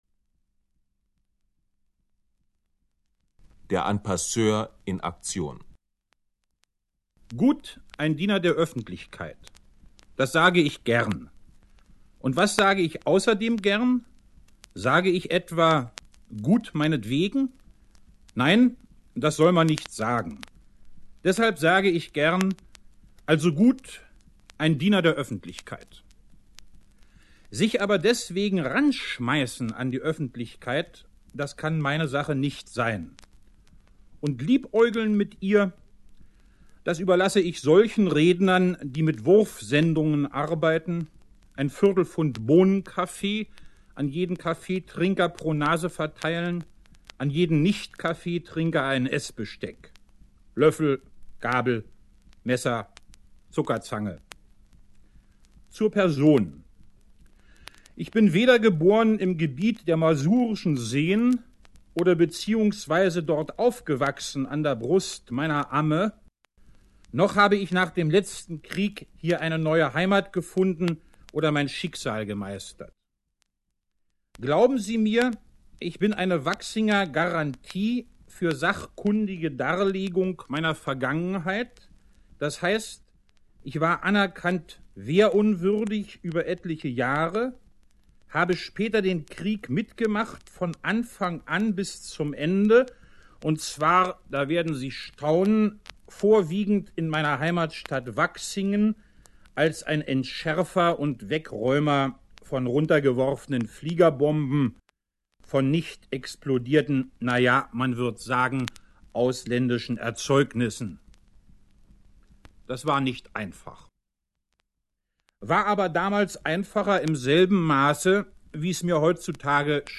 Günter Bruno Fuchs: Ein Ohr wäscht das andere vom Autor selbst gelesene Texte